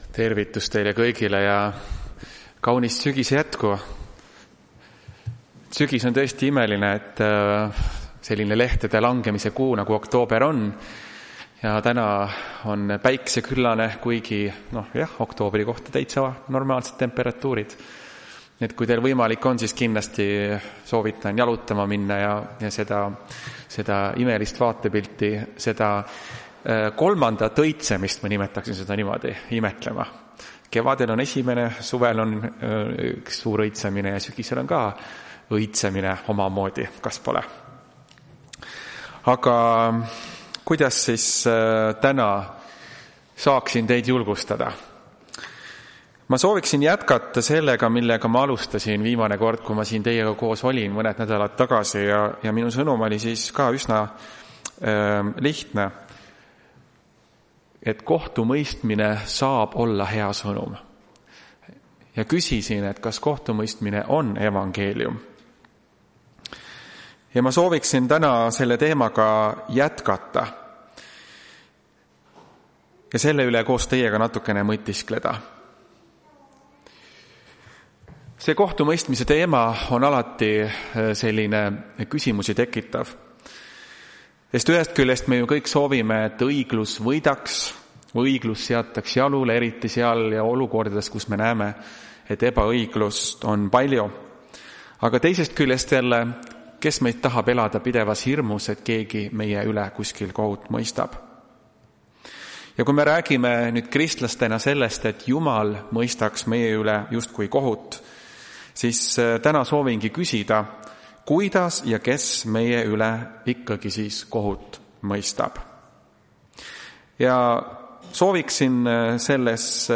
Tartu adventkoguduse 18.10.2025 teenistuse jutluse helisalvestis.